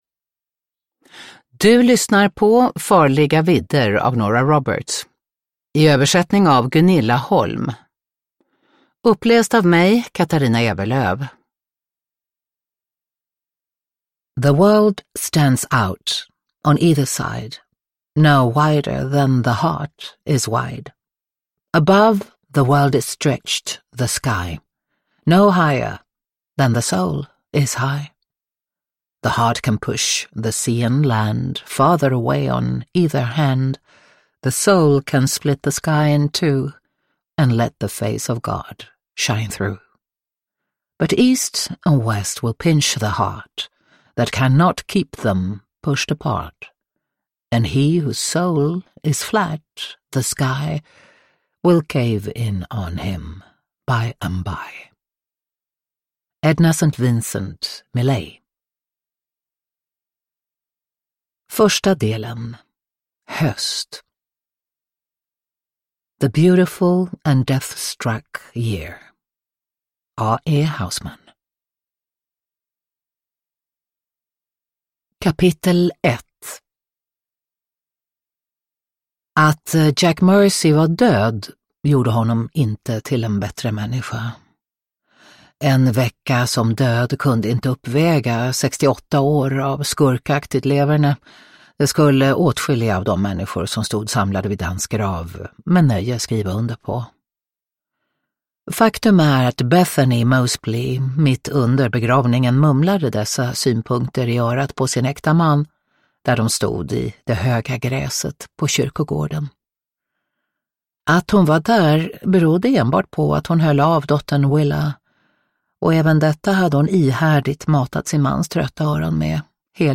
Farliga vidder – Ljudbok – Laddas ner
Uppläsare: Katarina Ewerlöf